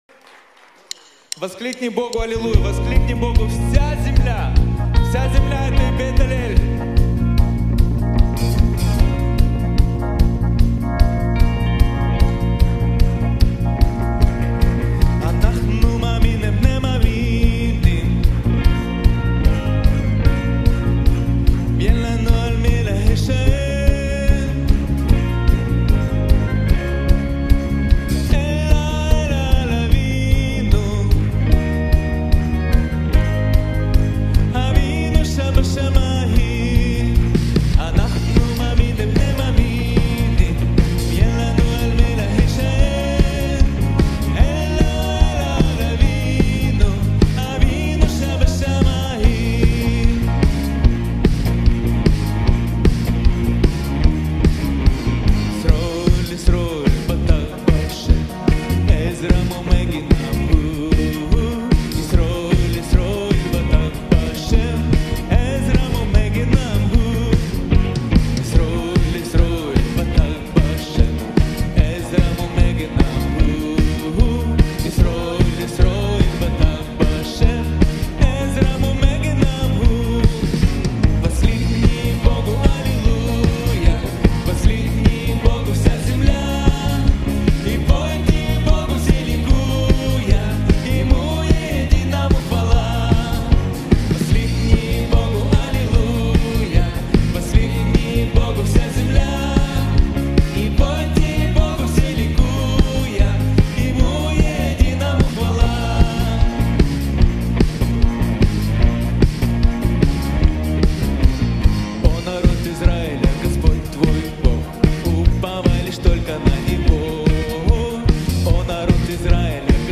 132 просмотра 373 прослушивания 9 скачиваний BPM: 140